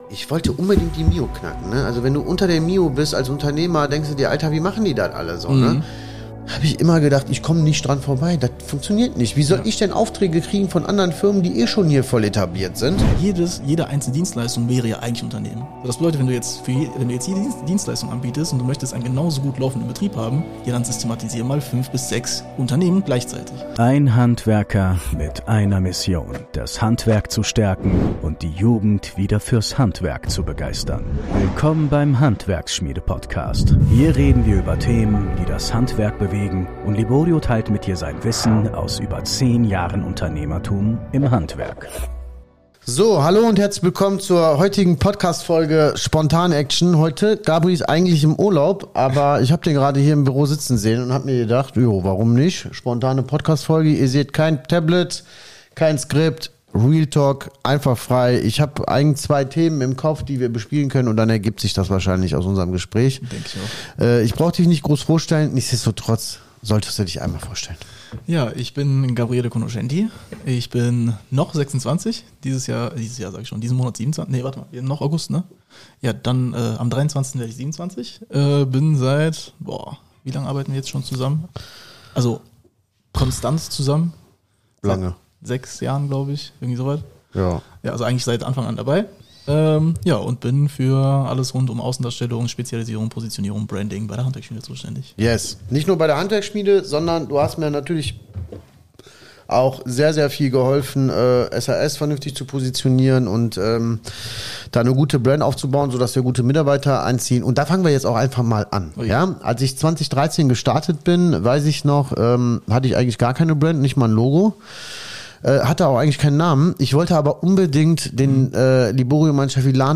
So ruinierst du deinen Betrieb mit zu vielen Leistungen | Interview